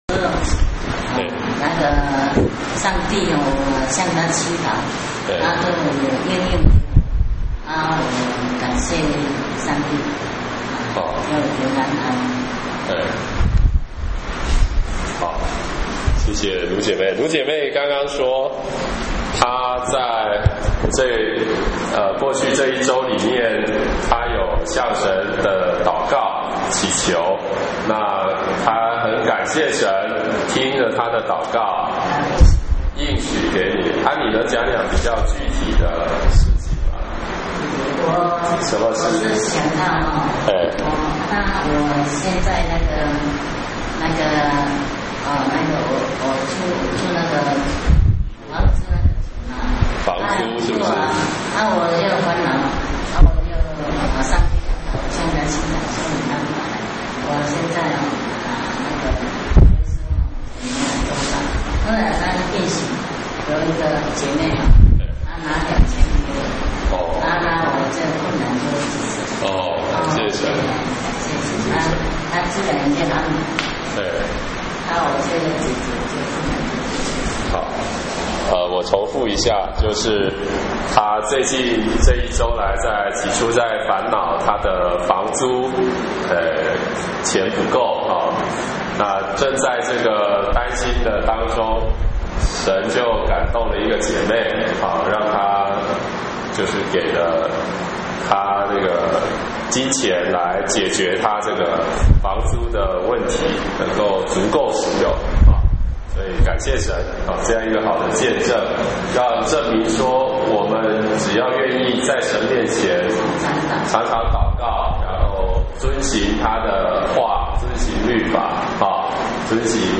（使徒行傳 5:12-6:7）-1 講解